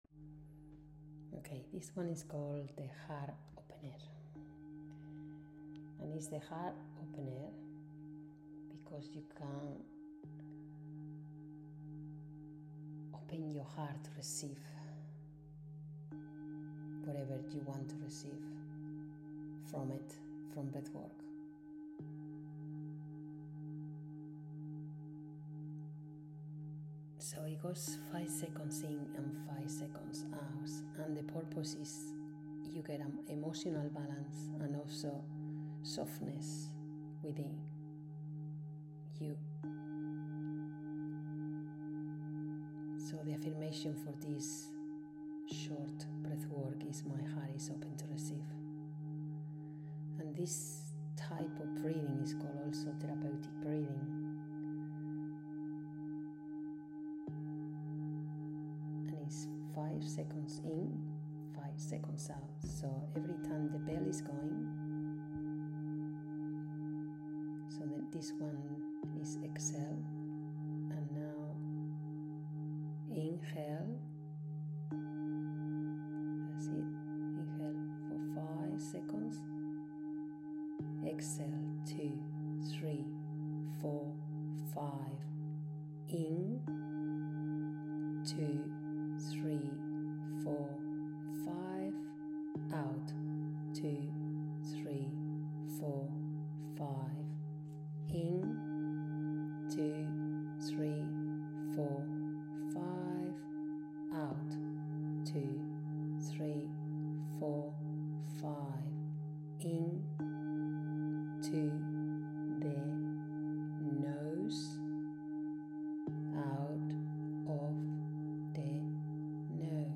Heart Opener guided BreathworkMP3 • 7722KB